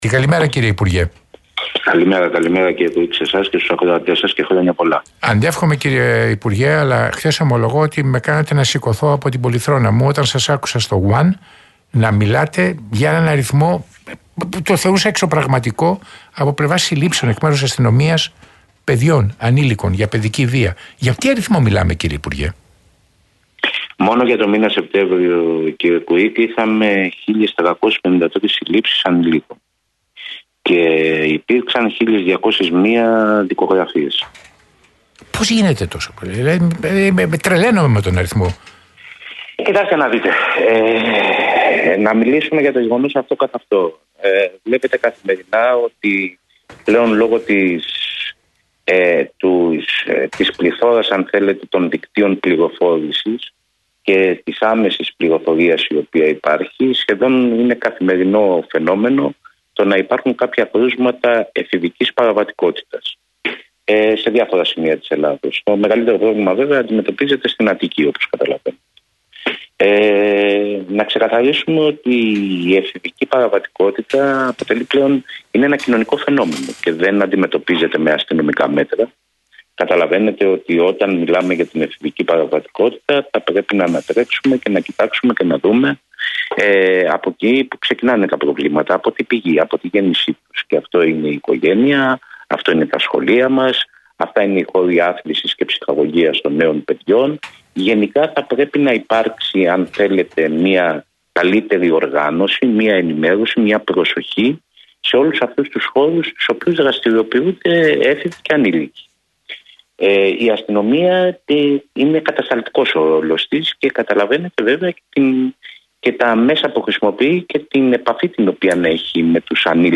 Για το φαινόμενο της παραβατικότητας ανηλίκων και τους μηχανισμούς αντιμετώπισης που πρέπει να αναπτυχθούν για την καταπολέμησή του μίλησε σήμερα, Παρασκευή, (27/10) στον Realfm και την εκπομπή του Τέρενς Κουίκ ο υφυπουργός Προστασίας του Πολίτη, Κώστας Κατσαφάδος.